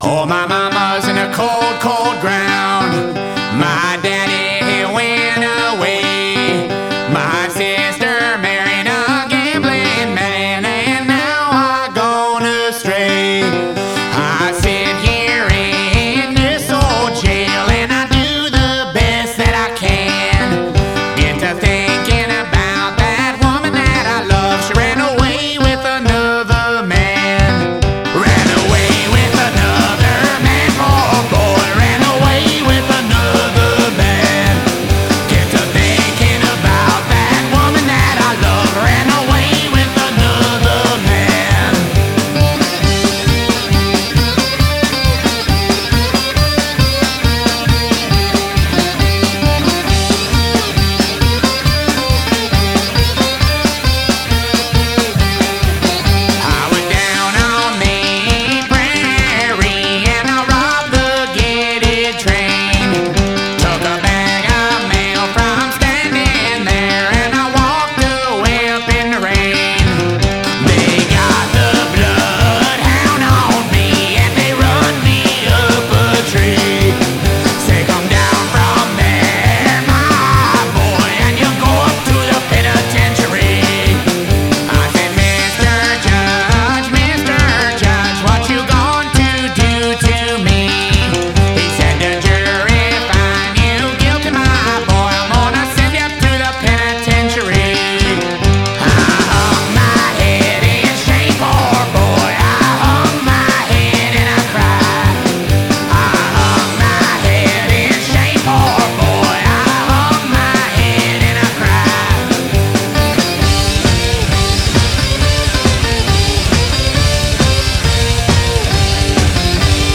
Bluegrass/Punk album